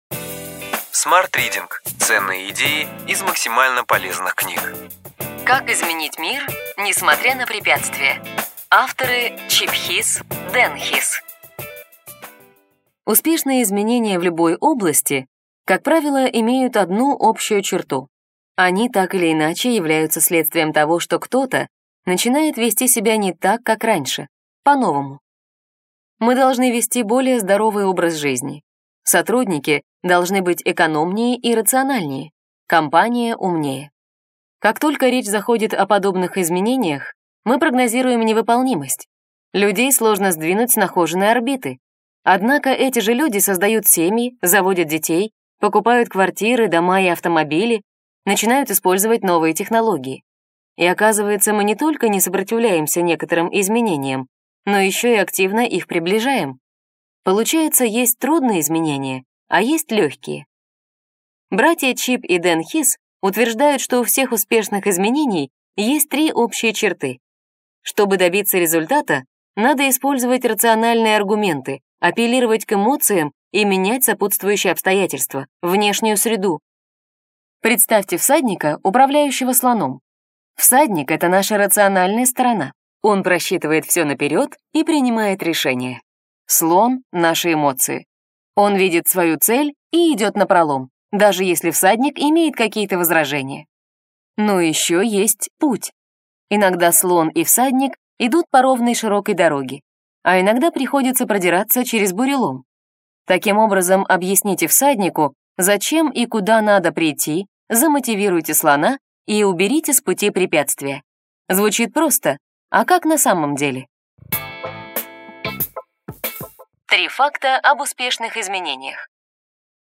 Аудиокнига Ключевые идеи книги: Как изменить мир, несмотря на препятствия.